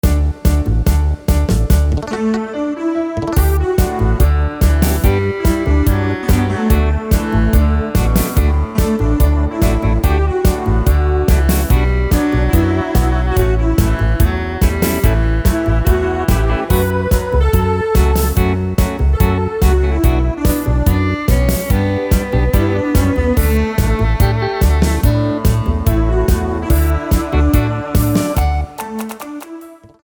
Rubrika: Hudba z filmů, TV, muzikály
Karaoke